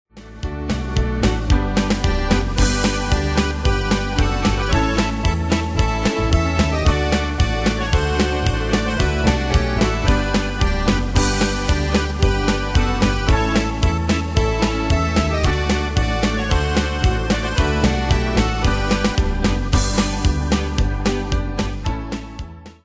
Folklore
Style: Folklore Ane/Year: 2002 Tempo: 112 Durée/Time: 5.08
Pro Backing Tracks